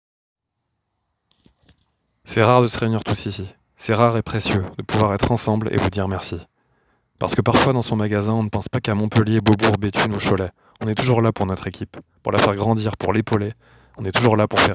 Voix off spot